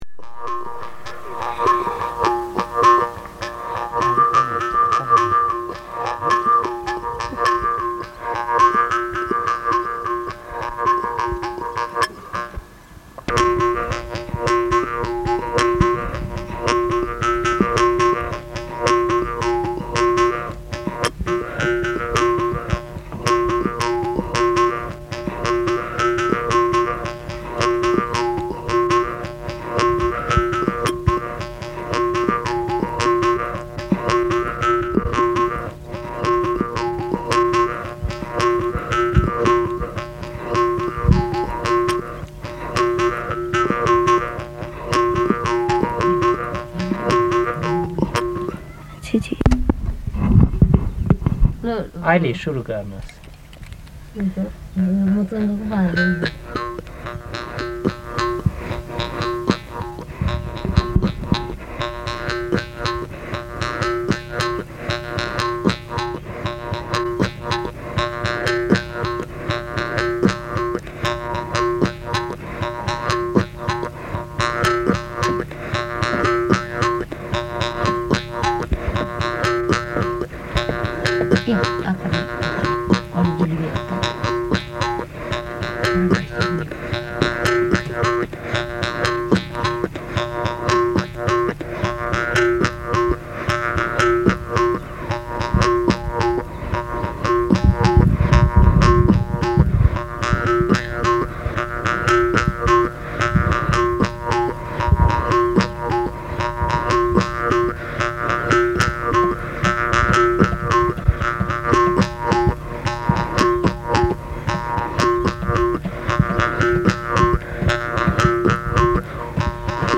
Man playing bināyā (jew's harp)
reel-to-reel recordings of music and spoken language
in Nepal and India between 1970 and 1981